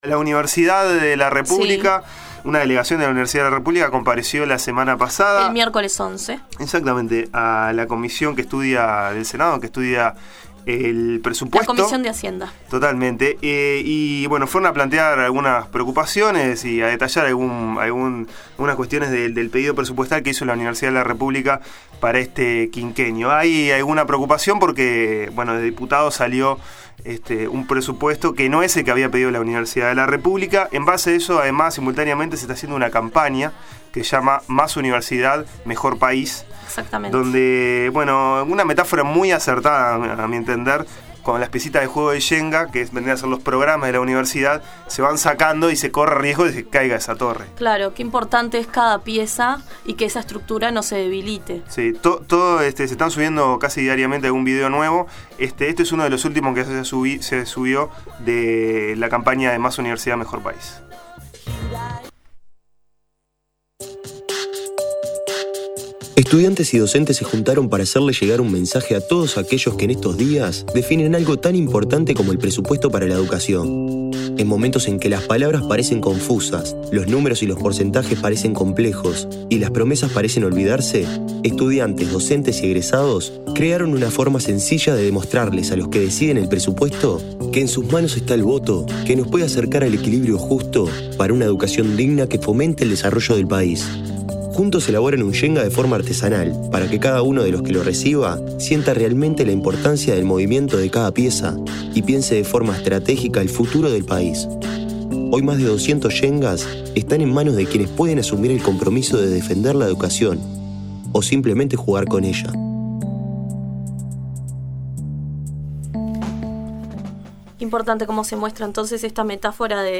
Audio: Entrevista al Rector Roberto Markarián